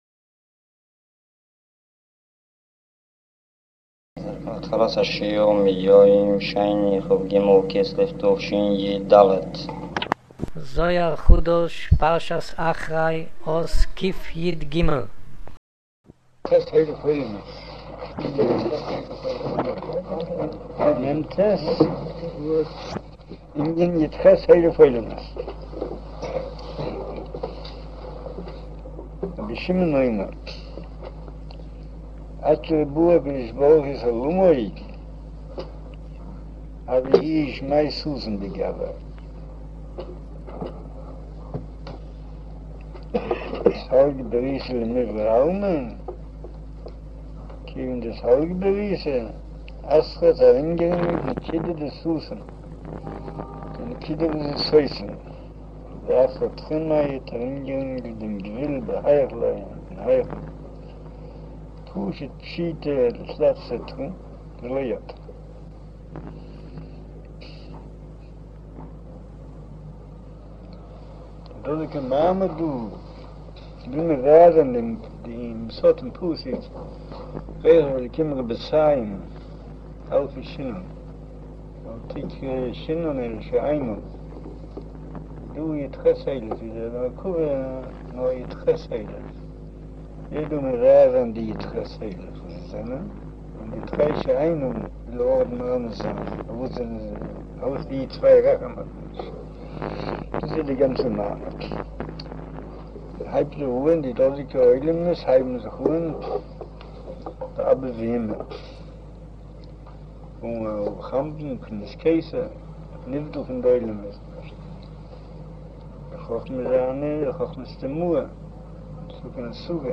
אודיו - שיעור מבעל הסולם זהר חדש, אחרי, אות קיג' - קטו'